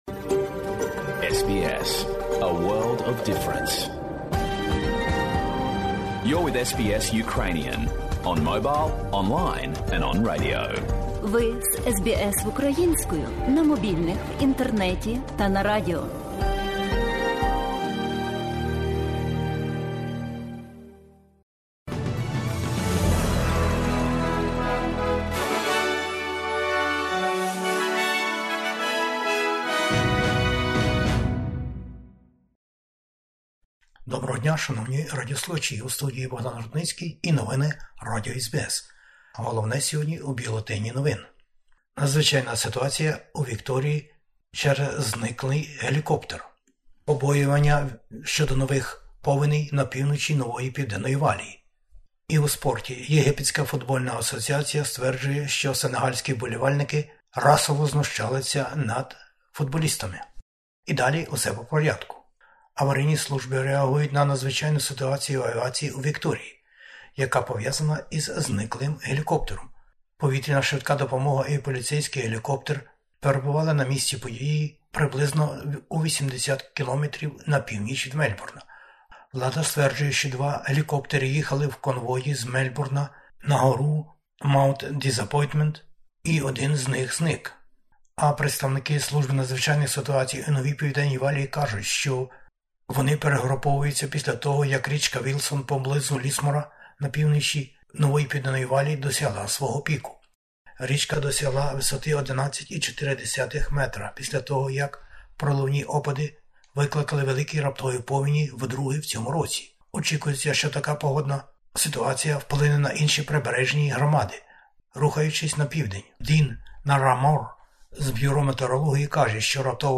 Бюлетень новин SBS українською мовою.